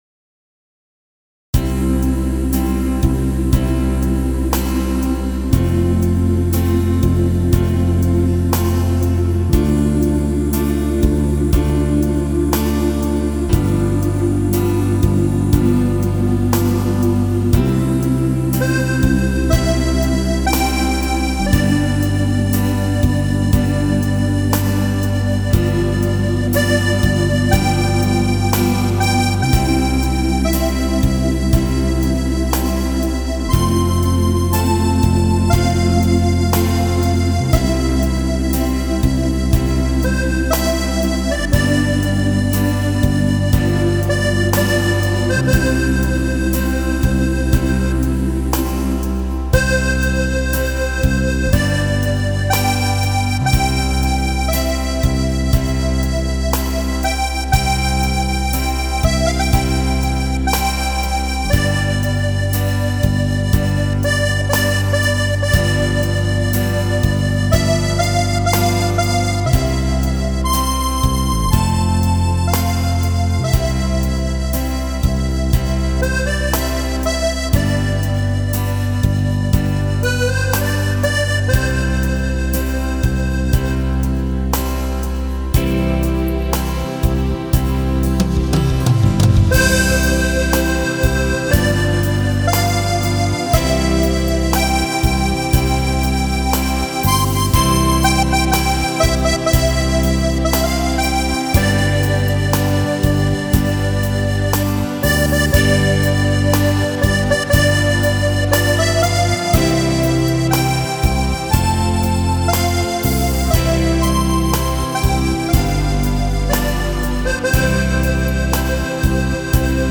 Sakrale Stücke